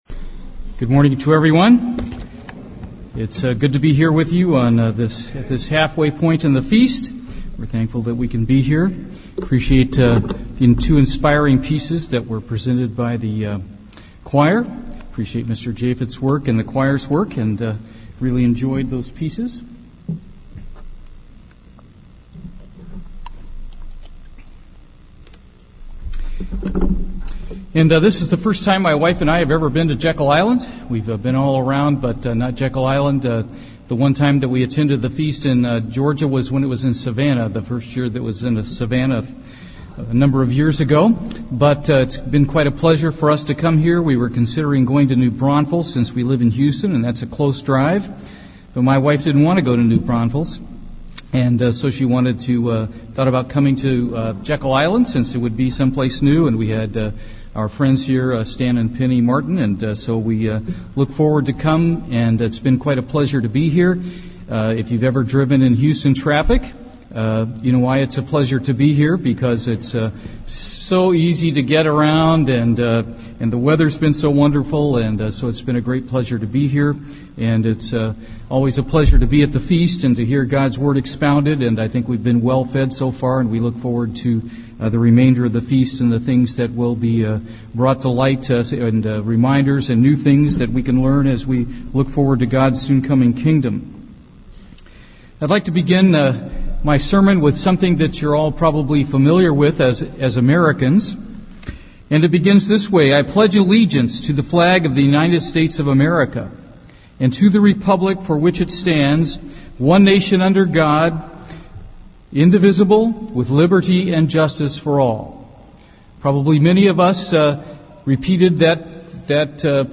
This sermon was given at the Jekyll Island, Georgia 2014 Feast site.